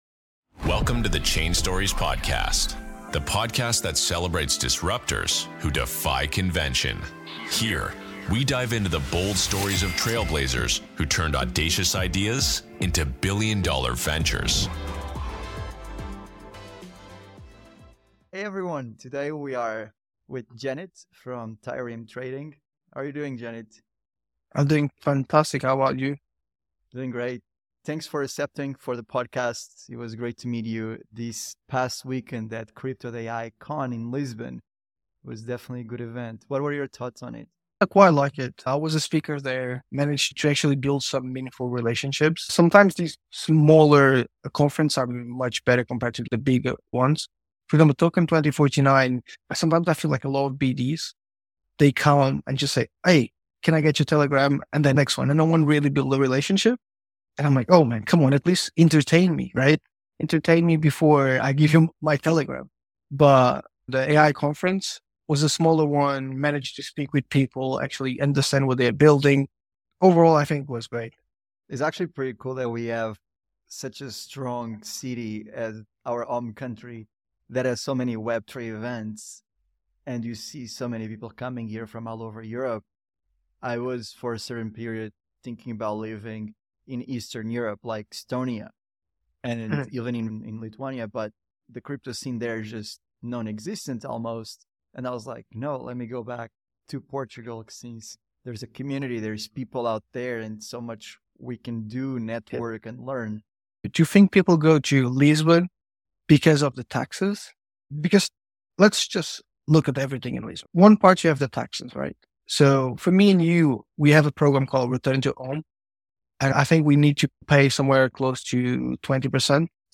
Real vs. Fake: What Drives Value in Crypto? - Interview with Trireme Trading